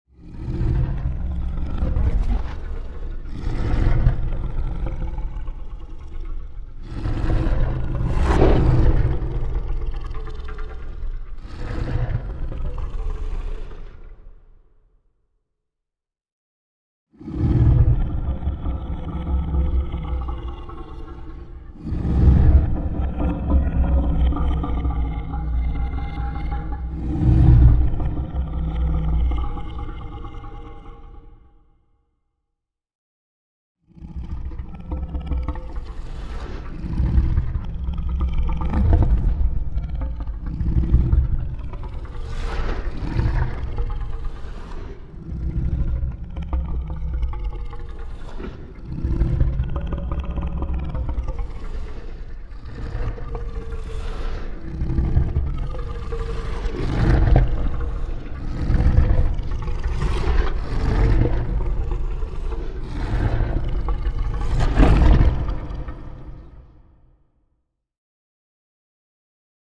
Звуки дракона
Грозный рык дракона